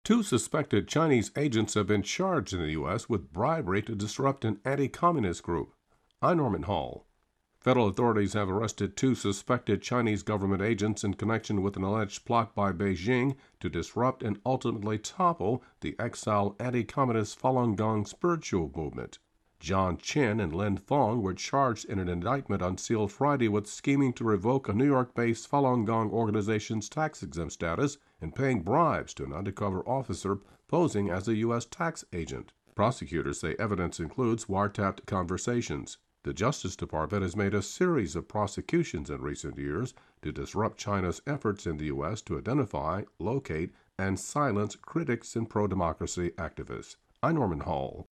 Two suspected Chinese agents have been charged in the U.S. with bribery to disrupt an anti-communist group. AP correspondent